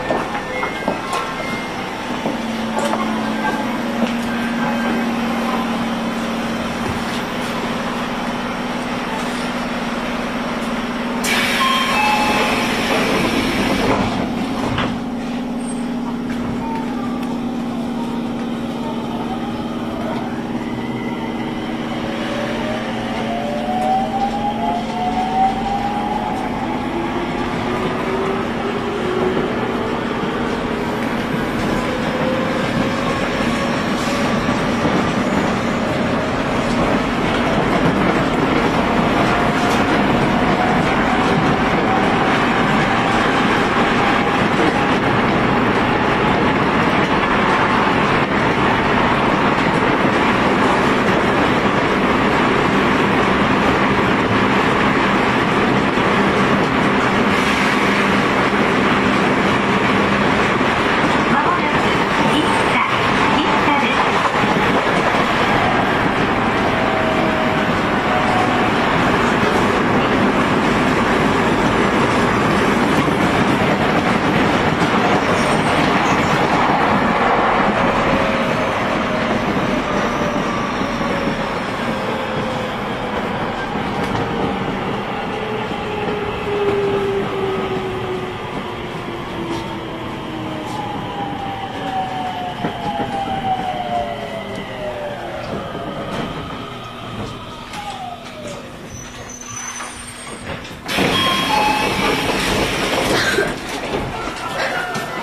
インバーター制御方式がGTOからIGBTに変更になるなど改善が図られています。
走行音
OE02 2000系 読売ランド前-生田 1:45 9/10 上の続きです。